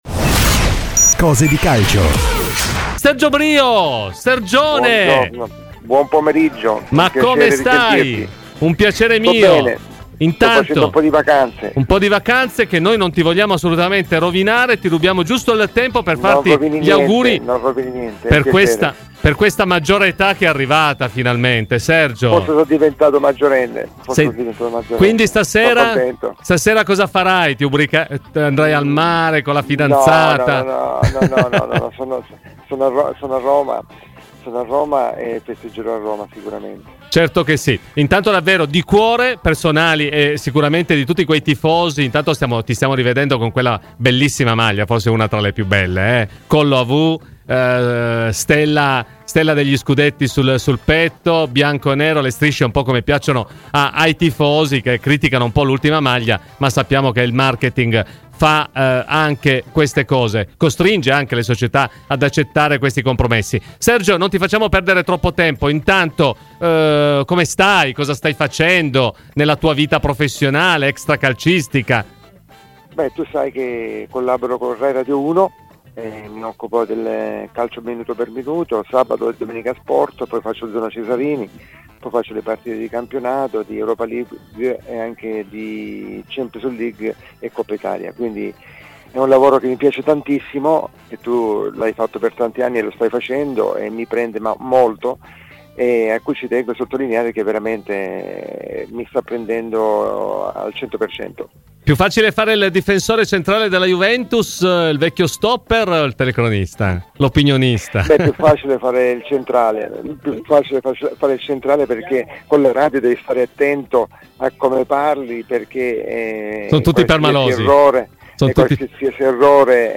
Ai microfoni di Radio Bianconera, nel corso di ‘Cose di Calcio’, è intervenuto l’ex bianconero Sergio Brio: “In questo momento sto collaborando con Radio Rai Uno, è un lavoro che mi piace moltissimo e che mi prende al 100%.